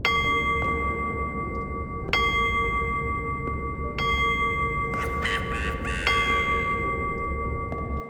cuckoo-clock-04.wav